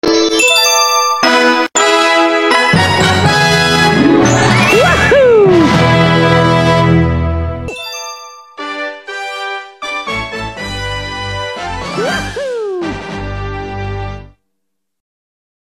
Collecting stars In super Mario sound effects free download
Collecting stars In super Mario Galaxy 2 and 1 sound effect